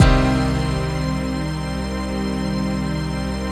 DM PAD5-7.wav